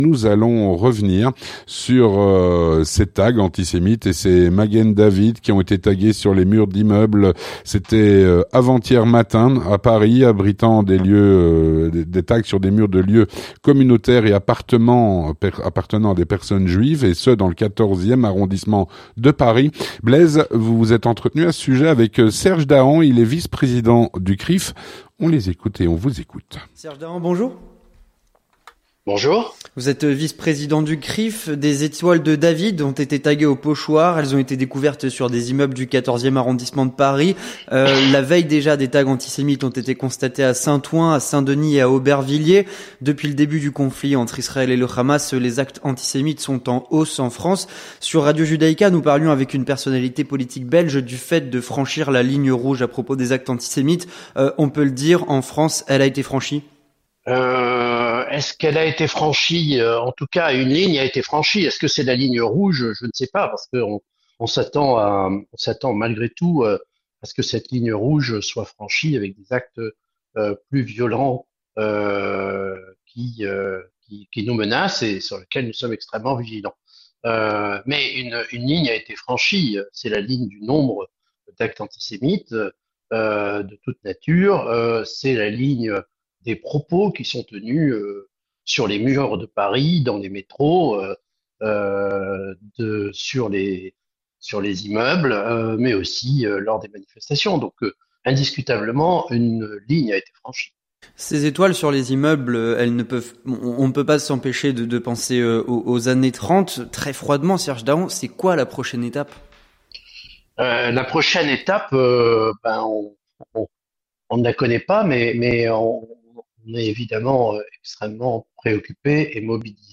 L'entretien du 18H - Des magen david ont été tagués sur des bâtiments où vivent des juifs.